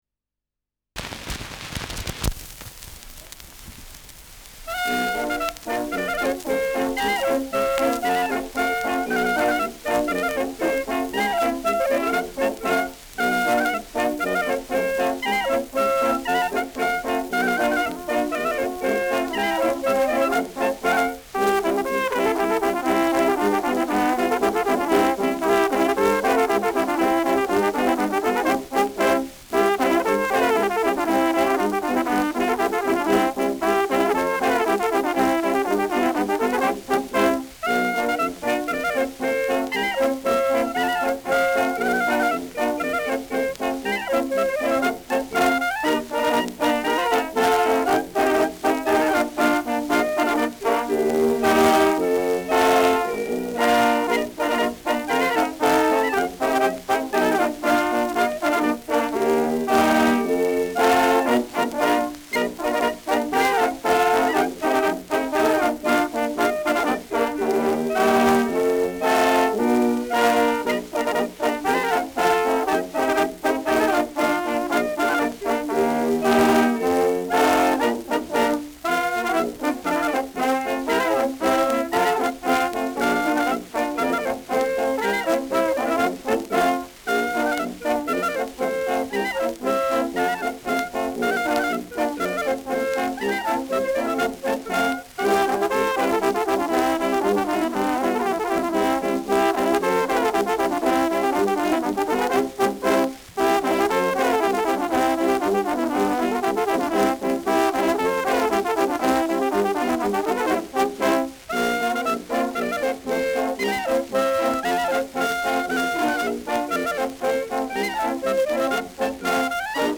Schellackplatte
Stärkeres Grundrauschen : Gelegentlich leichte Knacken : Verzerrt an lauteren Stellen
das auf der Subdominante beginnt